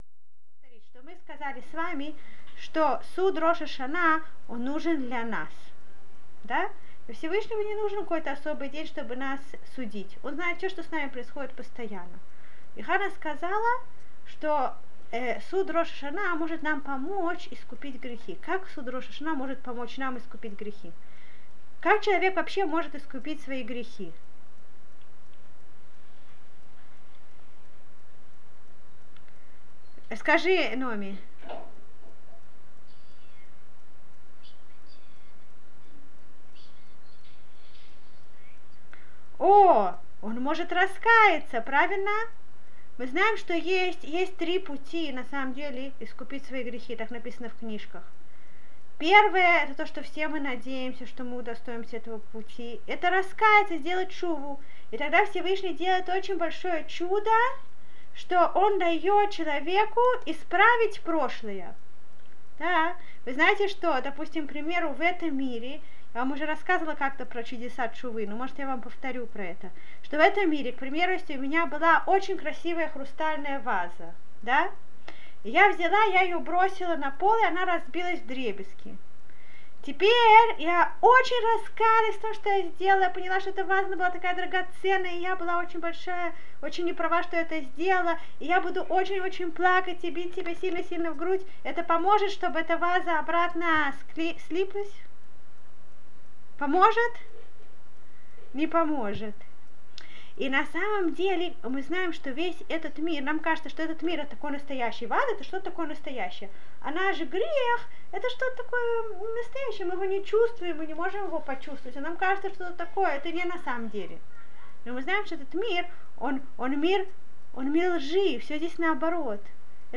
Урок для девочек старших классов .